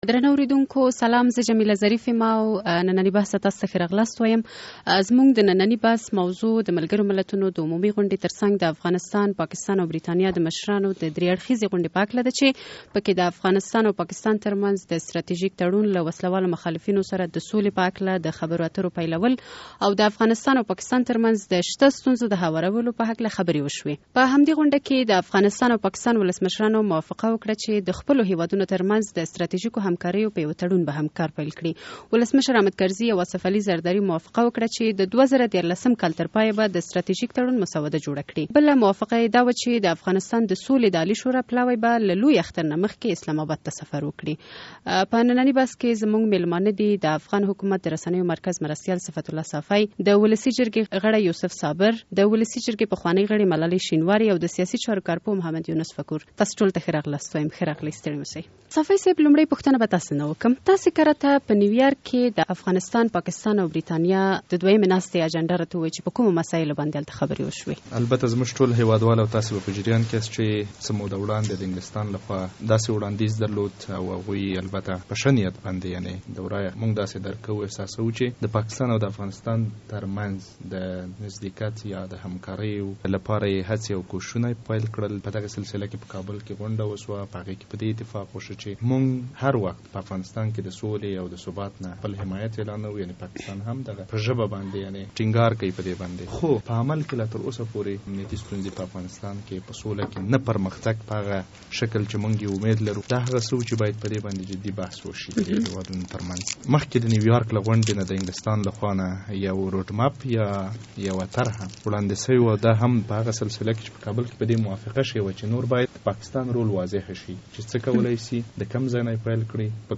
د ازادۍ راډیو د اوونۍ بحث